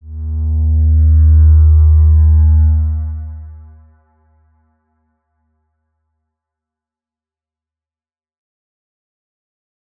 X_Windwistle-D#1-pp.wav